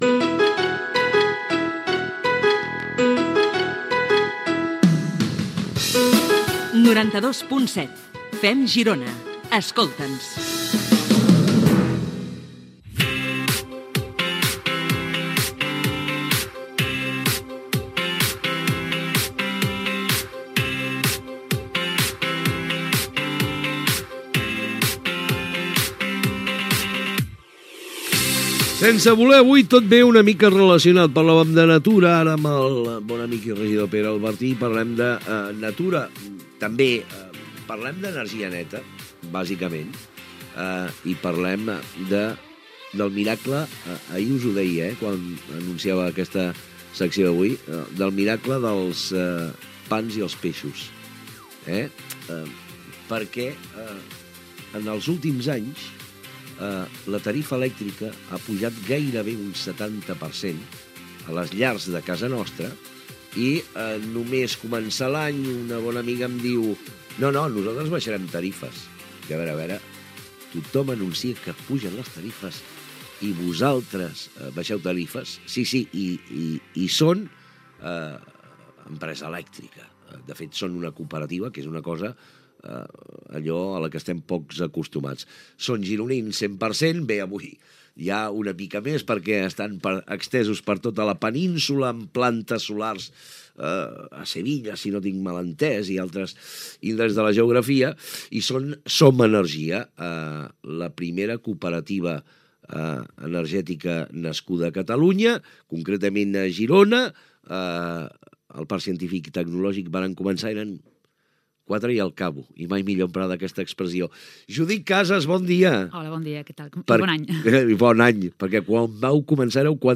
Indicatiu de l'emissora.
Info-entreteniment